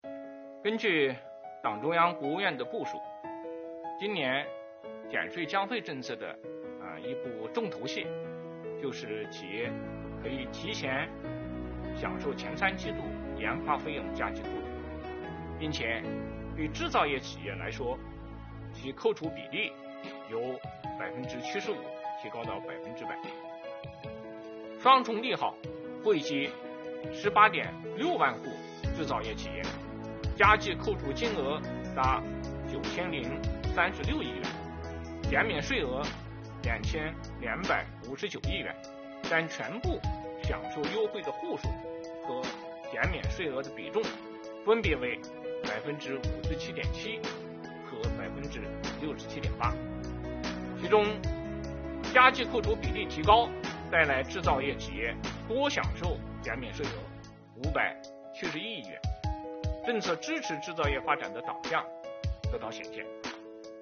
11月5日，国务院新闻办公室举行国务院政策例行吹风会，国家税务总局副局长王道树介绍制造业中小微企业缓税政策等有关情况，并答记者问。